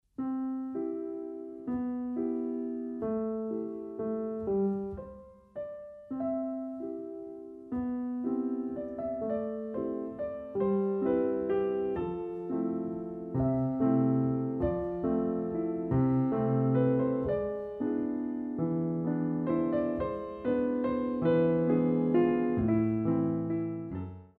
Young dancers Ballet Class
The CD is beautifully recorded on a Steinway piano.
Ronds de jambe